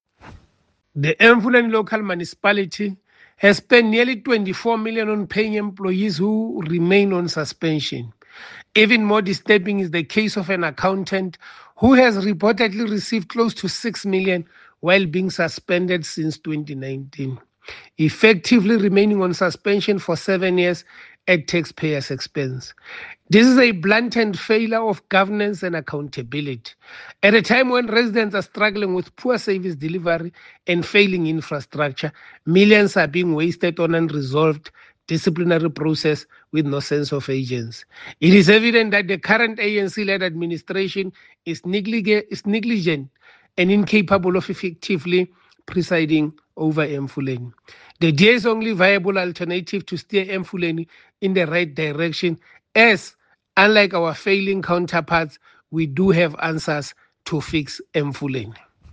English soundbite by  Kingsol Chabalala MPL